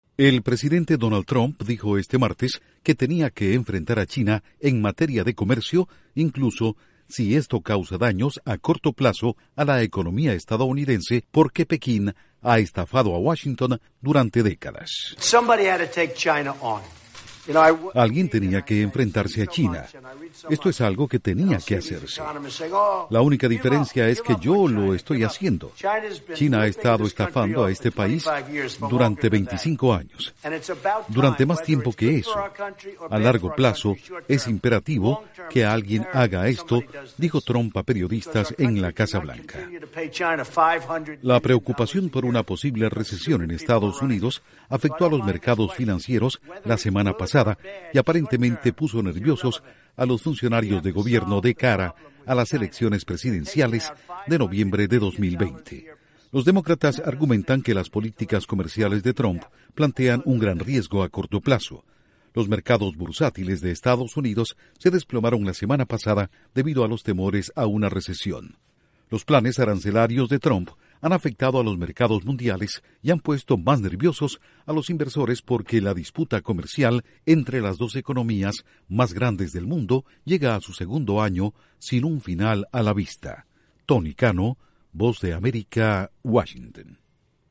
Trump dice que había que "enfrentar a China", sin importar el impacto a corto plazo. Informa desde la Voz de América en Washington